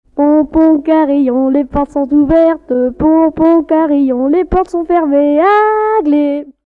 Enfantines - rondes et jeux
Pièce musicale éditée